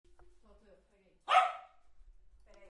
Dog_bark.mp3